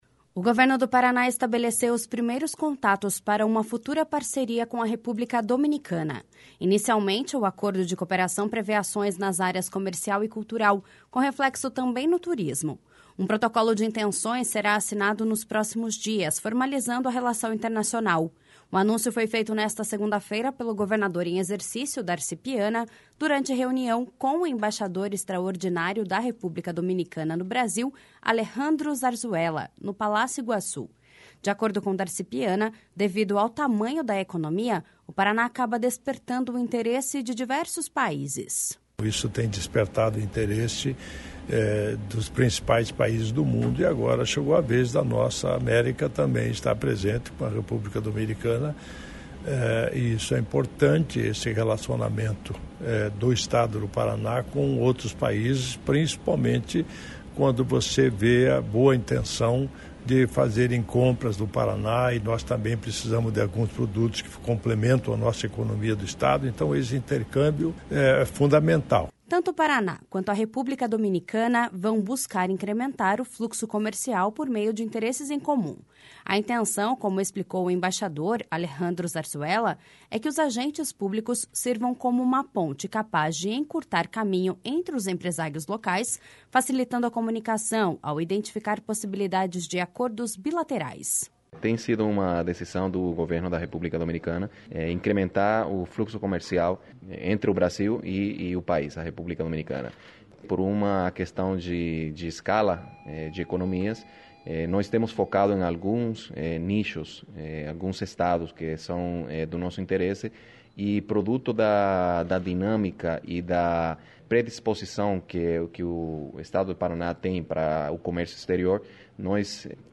De acordo com Darci Piana, devido ao tamanho da economia, o Paraná acaba despertando o interesse de diversos países.// SONORA DARCI PIANA.//
A intenção, como explicou o embaixador Alejandro Zarzuela, é que os agentes públicos sirvam como uma ponte capaz de encurtar caminho entre os empresários locais, facilitando a comunicação ao identificar possibilidades de acordos bilaterais.// SONORA ALEJANDRO ZARZUELA.//
O secretário de Estado da Comunicação Social e Cultura, Hudson José, ressaltou que esse relacionamento é importante e reforça a boa intenção de ambas as partes em se conhecerem melhor.// SONORA HUDSON JOSÉ.//